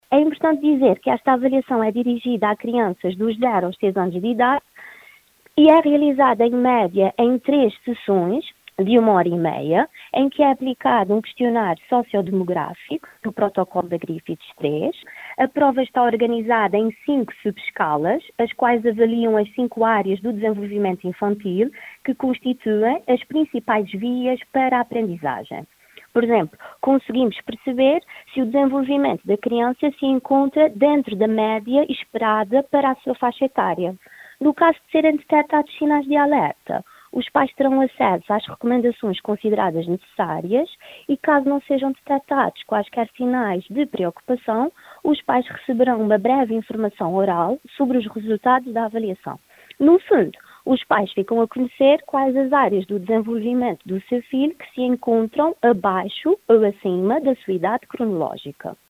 em entrevista à Altântida